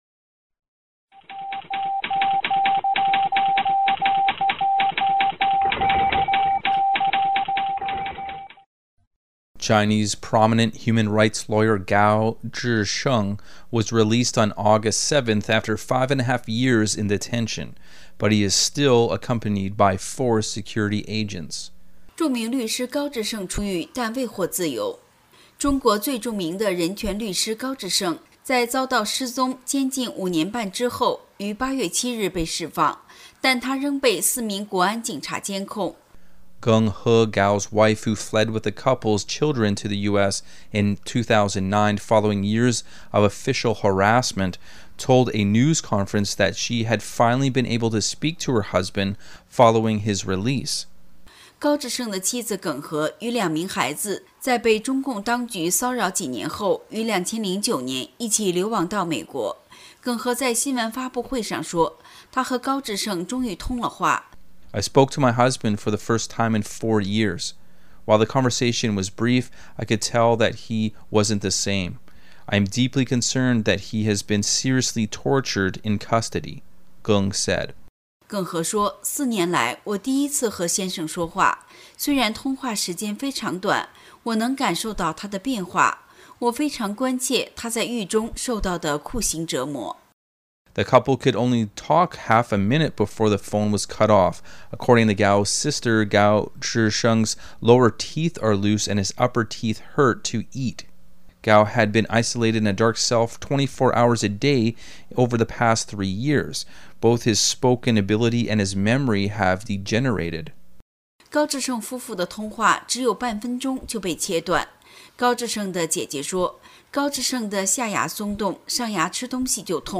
Type: News Reports
128kbps Mono